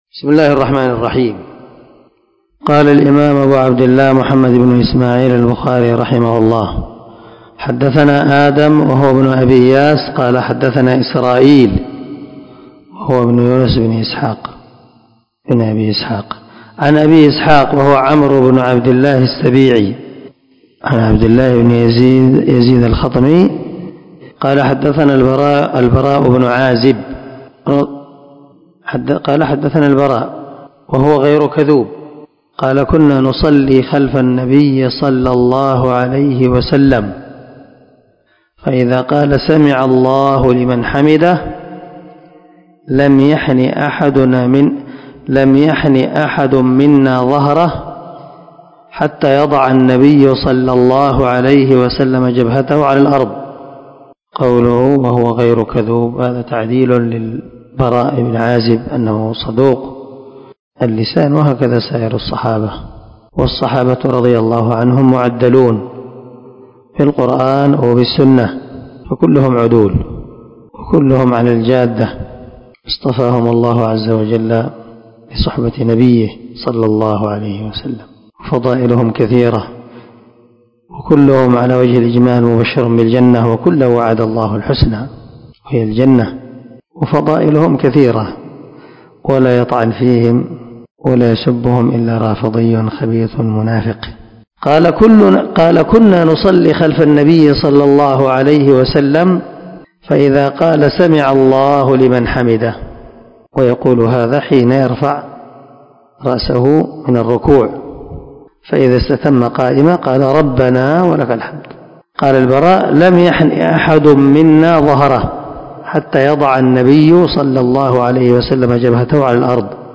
521الدرس 104من شرح كتاب الأذان حديث رقم ( 811 – 813 ) من صحيح البخاري